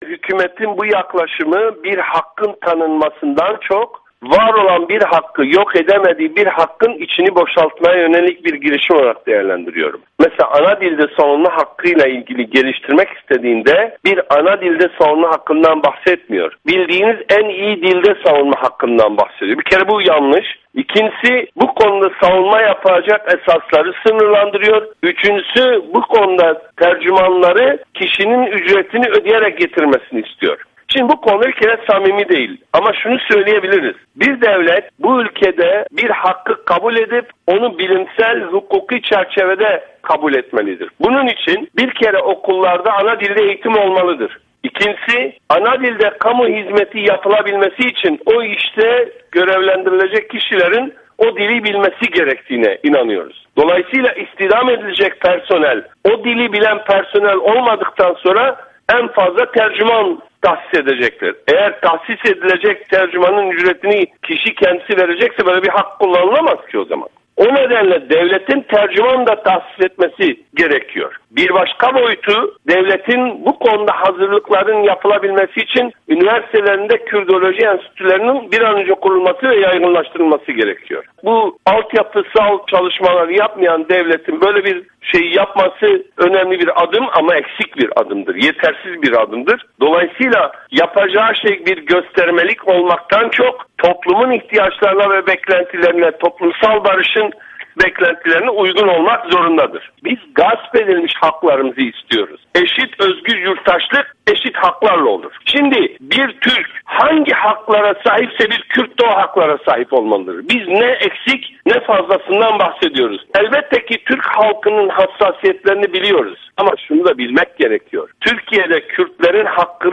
Abdullah Demirbaş ile Söyleşi